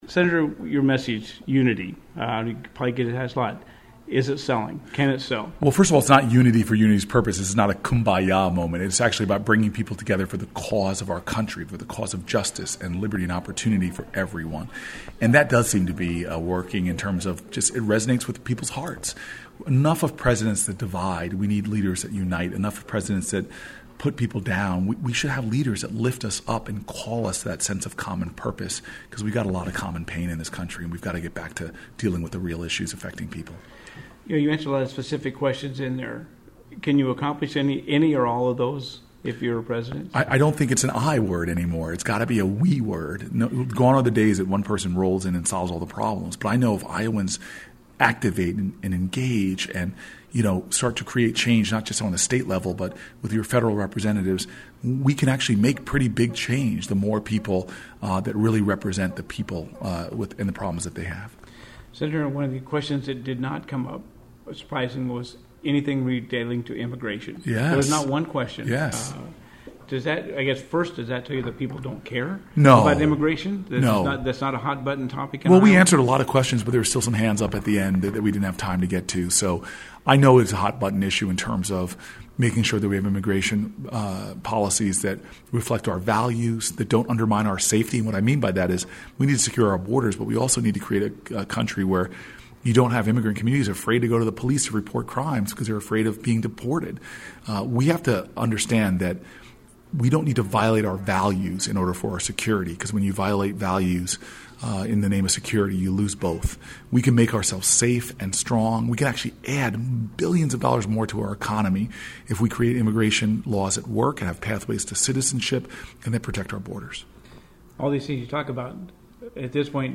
Listen to question and answer session with KROS NEWS